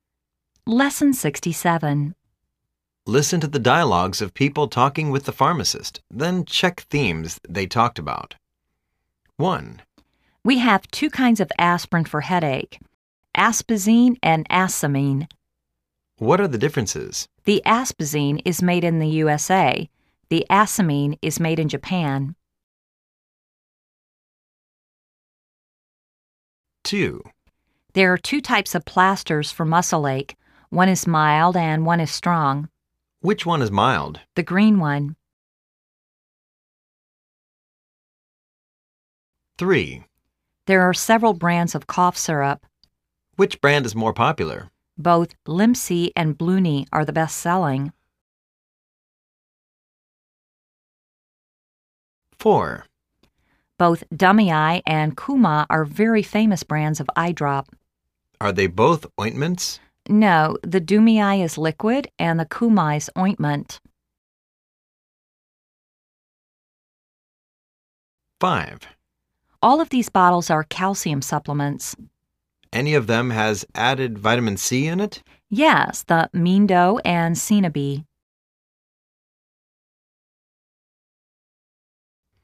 Listen to the dialogues people talking with the pharmacist.